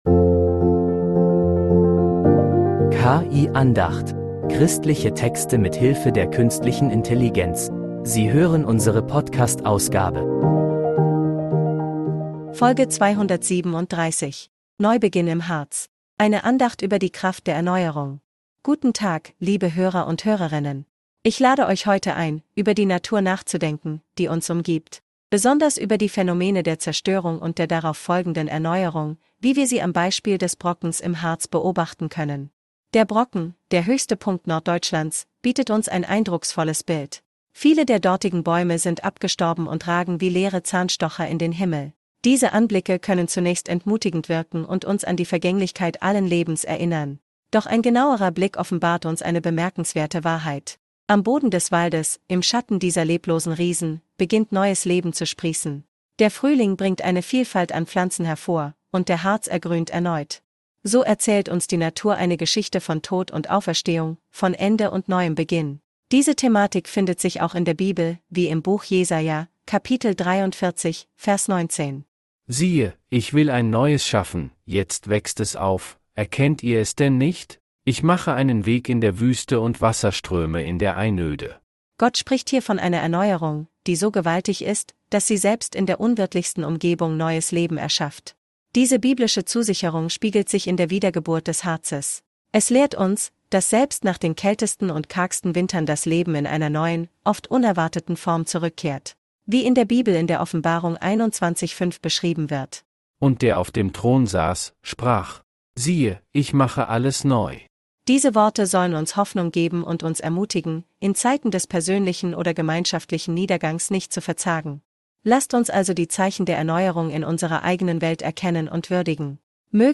Eine Andacht über die Kraft der Erneuerung